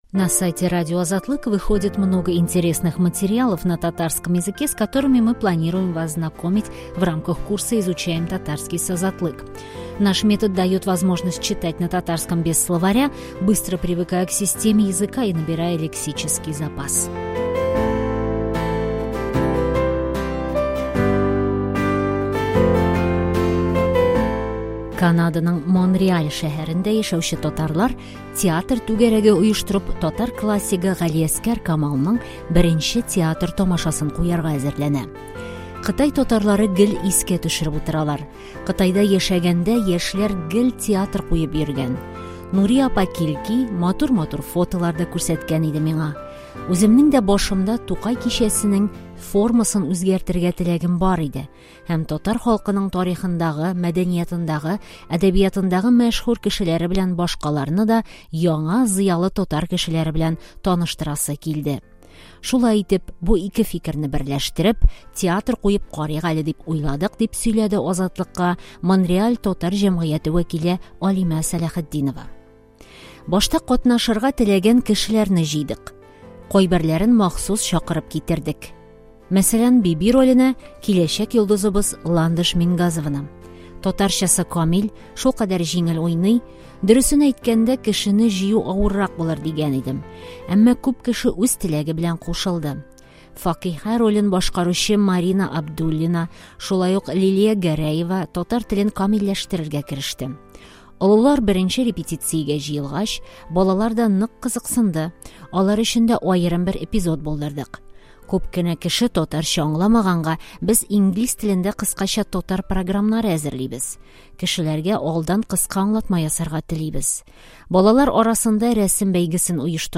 Как обычно, даем текст с подстрочным переводом и аудиосопровождением.
Также, обратите внимание, что специально для нашего проекта мы озвучили этот текст.